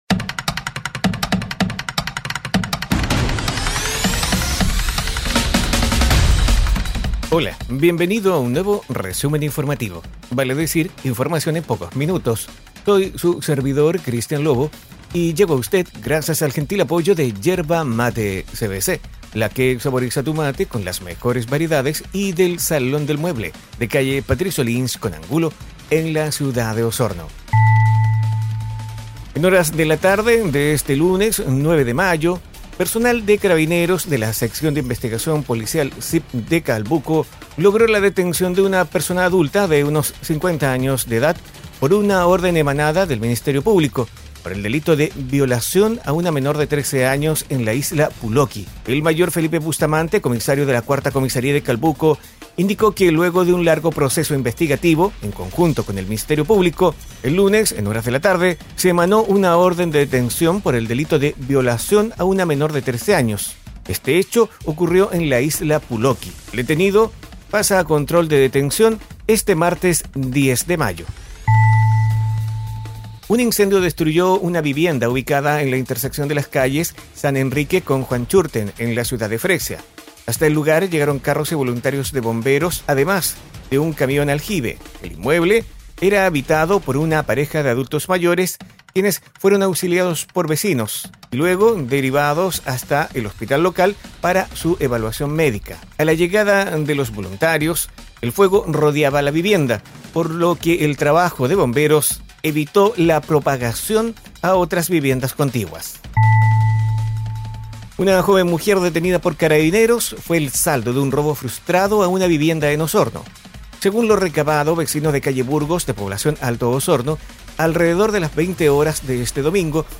Resumen informativo es un audio podcast con una decena informaciones en pocos minutos, enfocadas en la Región de Los Lagos